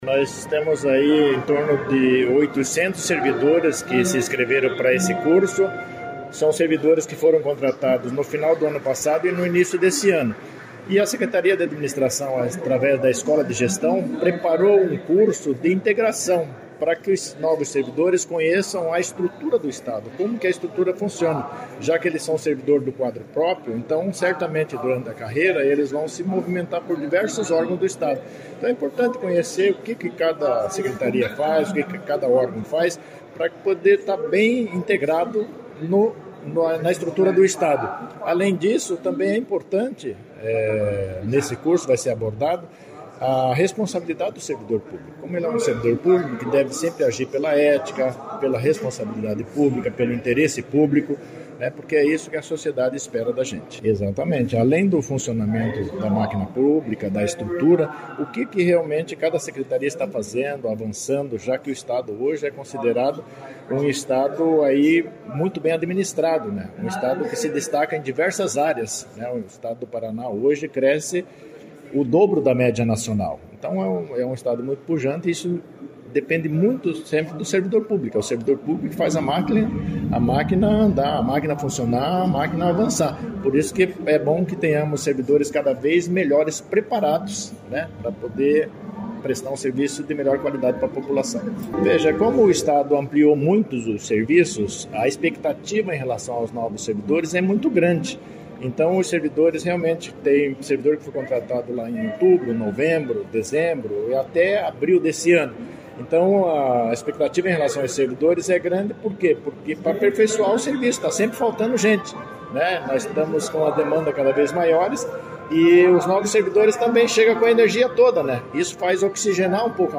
Sonora do secretário Estadual do Administração e Previdência, Luizão Goulart, sobre a a integração de 865 novos servidores